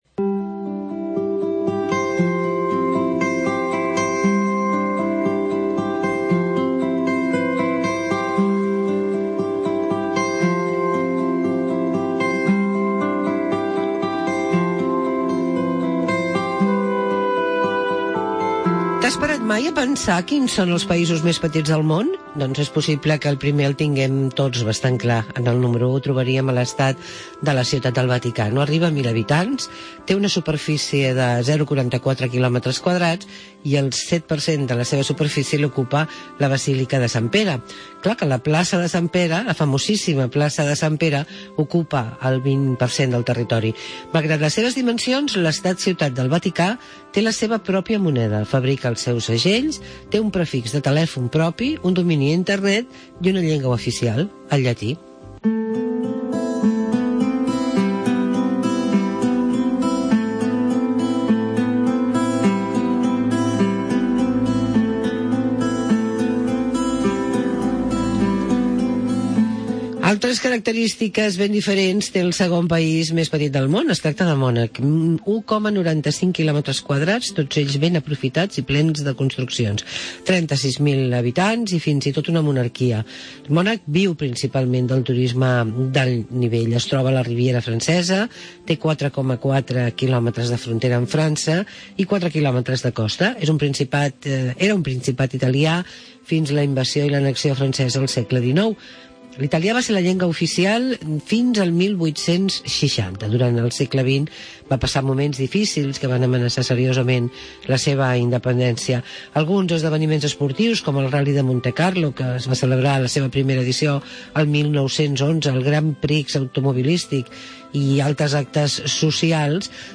Escuchamos un reportaje que nos habla de los paises más pequeños del mundo